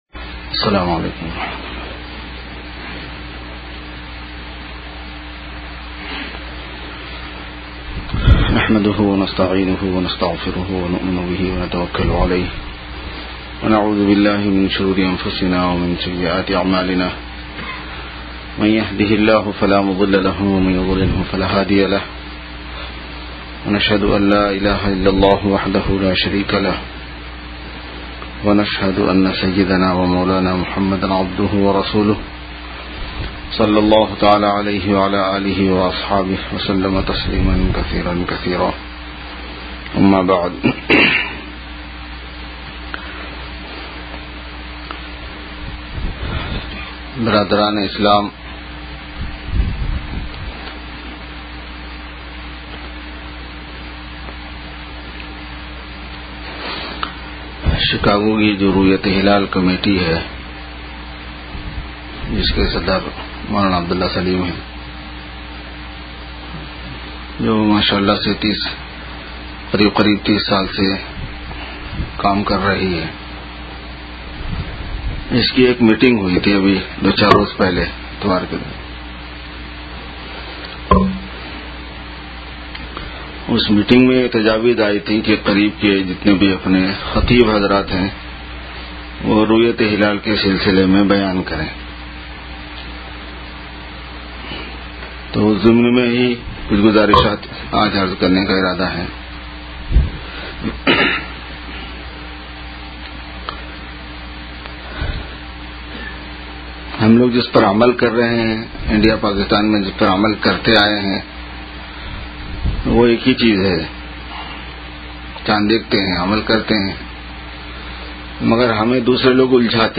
Khutbat e Juma 08-Jun-2012